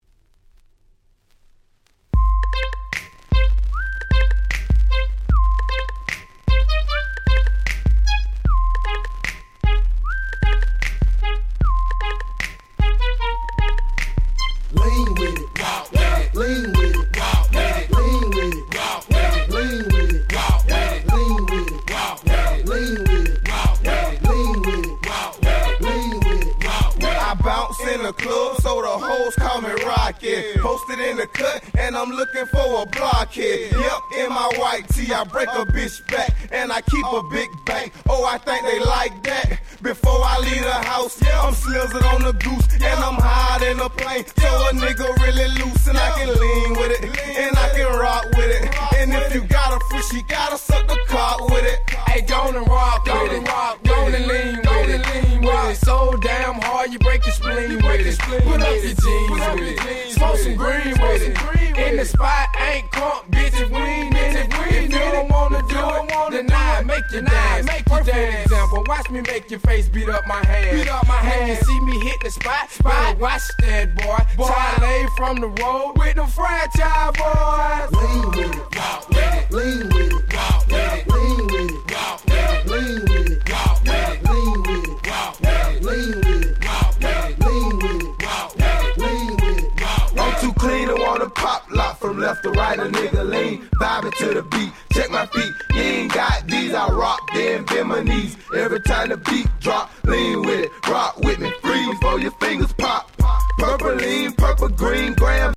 06' Super Hit Southern Hip Hop !!
スッカスカなのにブッリブリ。
当時初めて聴いた時は非常に不思議な質感に感じましたが、この曲のヒットを皮切りにコレ系のスカスカBeatが大流行！！